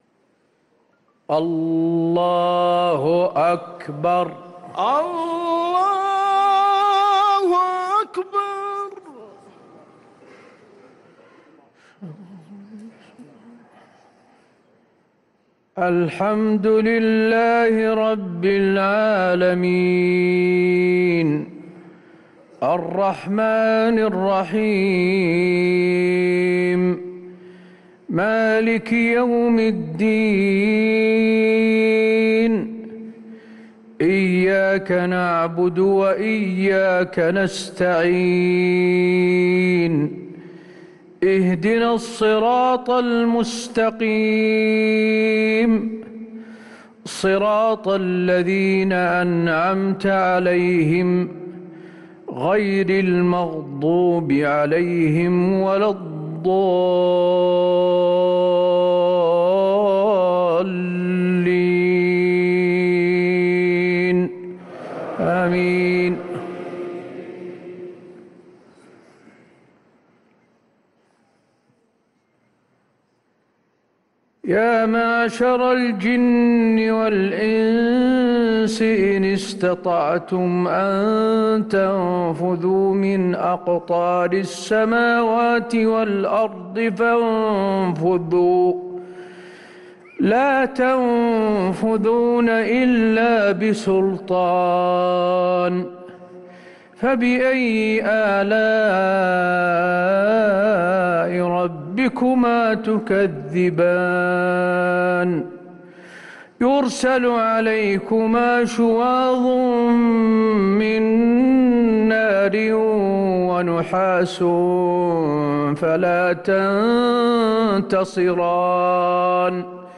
صلاة العشاء للقارئ حسين آل الشيخ 25 ربيع الآخر 1445 هـ